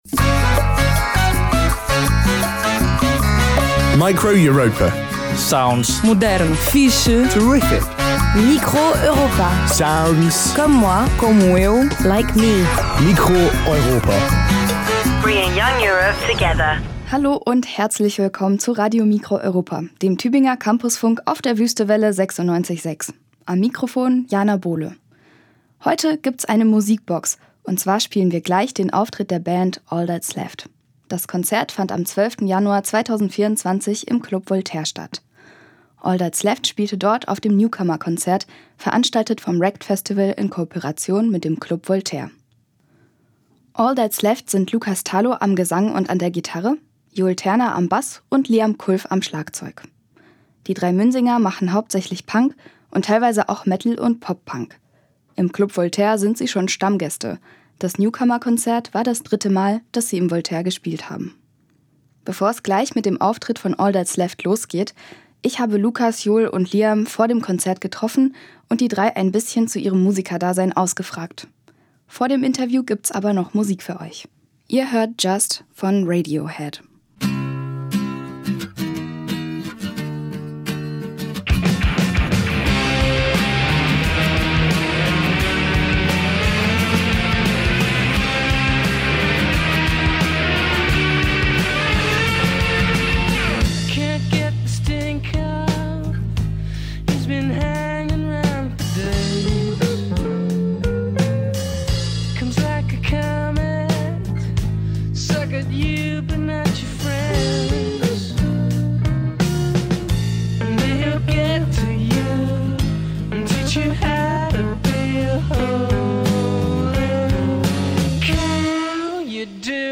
am Gesang und an der Gitarre
am Bass
Live-Aufzeichnung, geschnitten